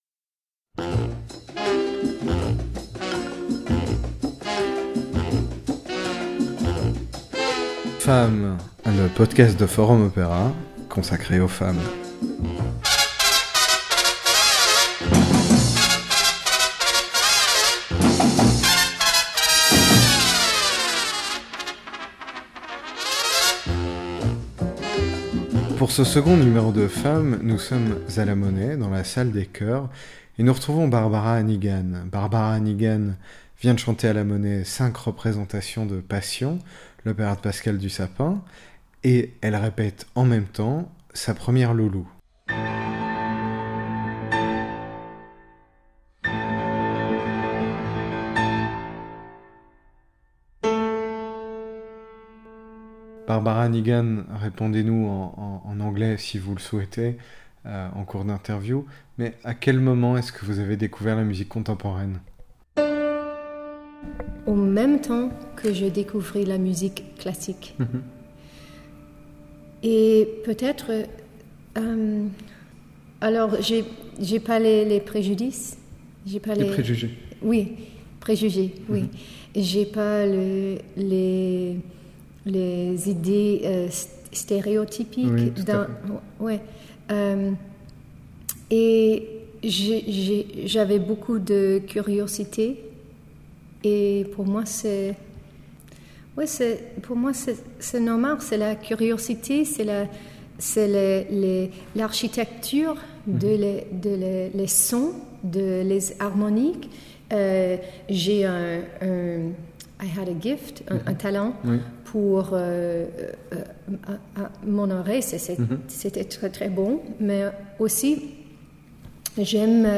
Nous l’avons rencontrée alors qu’elle répétait Lulu de Berg à La Monnaie. Interview